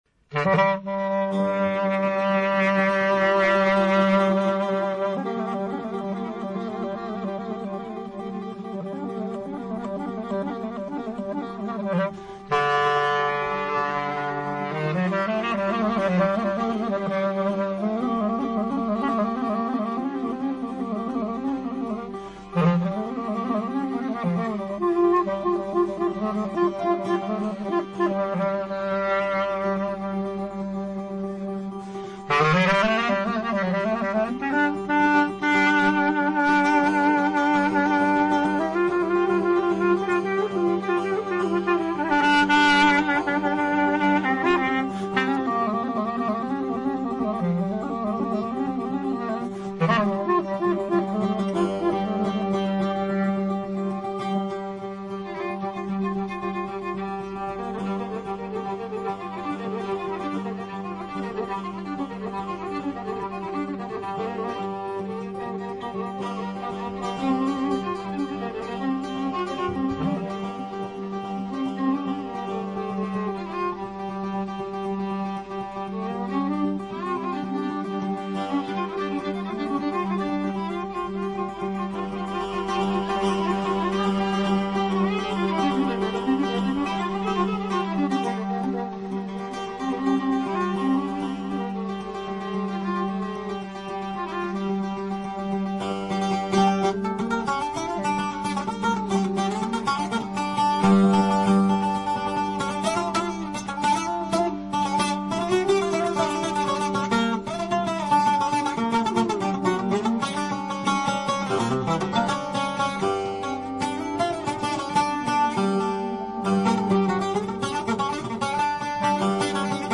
Ένα μουσικό αφιέρωμα με παραδοσιακά δημοτικά τραγούδια